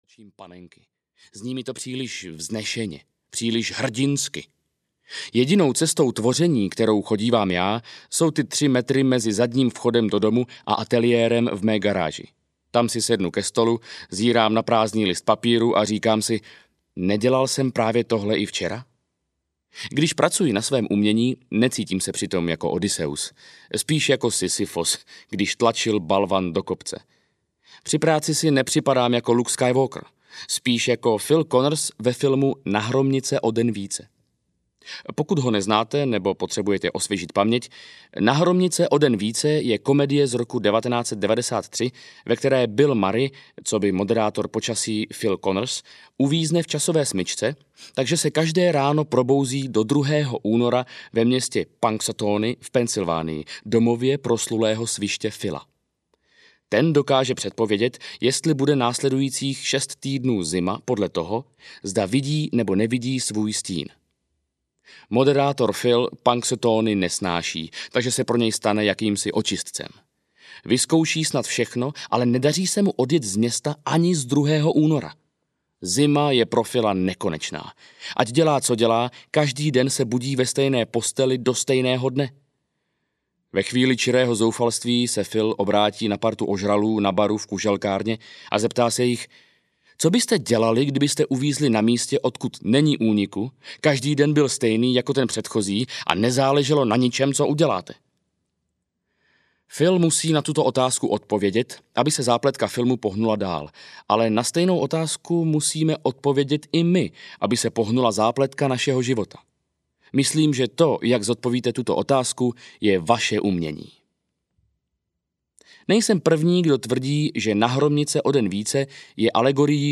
Jeď dál audiokniha
Ukázka z knihy